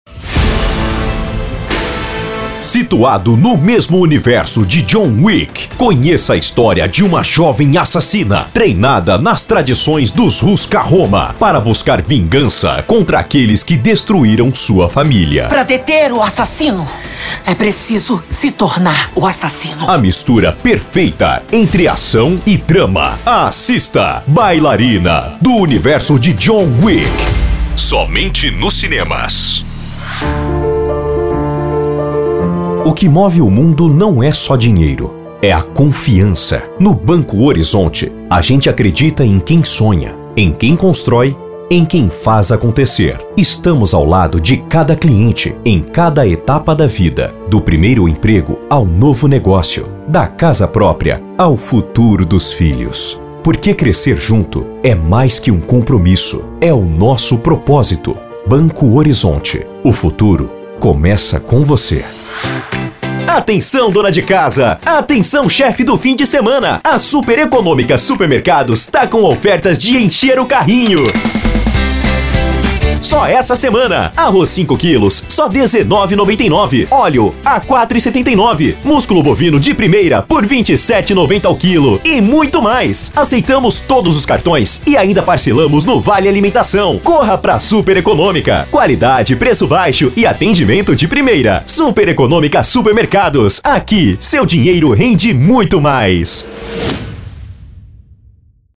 Spot Comercial
Vinhetas
Chamada de Festa
VT Comercial
Gravação Política
Padrão
Impacto
Animada